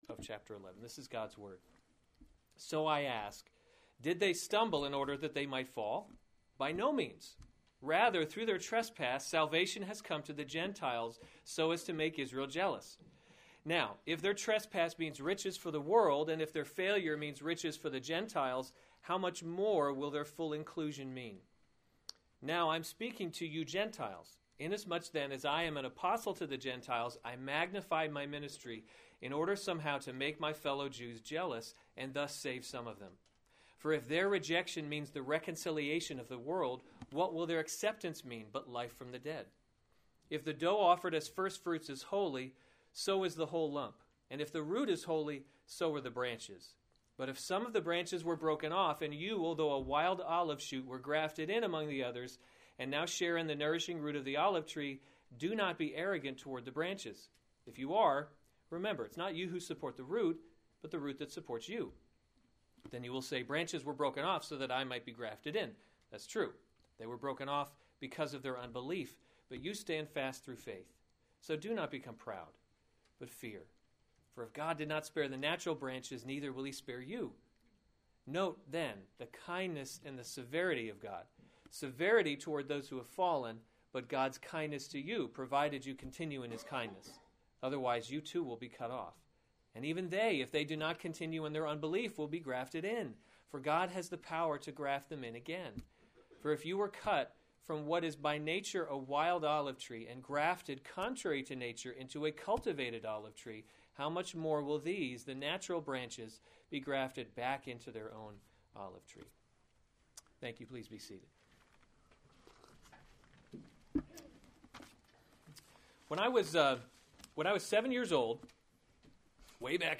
January 17, 2015 Romans – God’s Glory in Salvation series Weekly Sunday Service Save/Download this sermon Romans 11:11-24 Other sermons from Romans Gentiles Grafted In 11 So I ask, did they […]